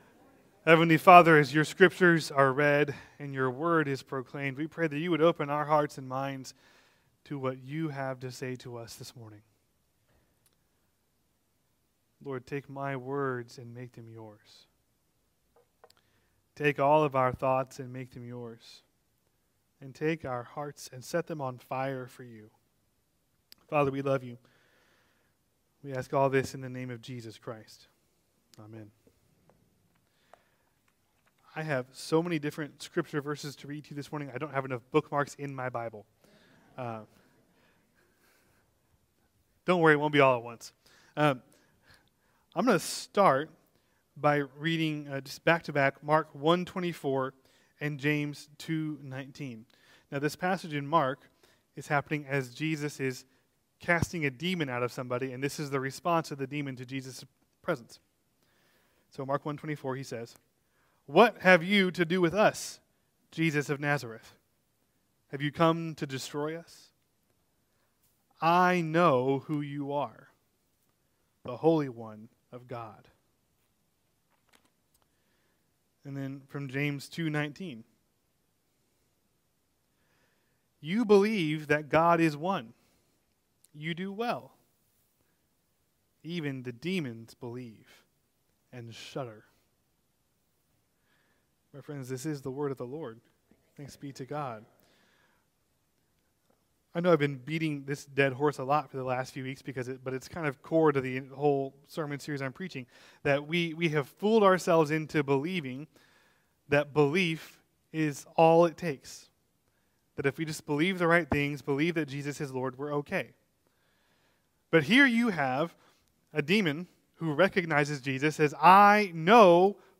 Sermons | Asbury Methodist Church